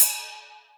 Index of /VEE/VEE2 Cymbals/VEE2 Rides
VEE2 Ride 28.wav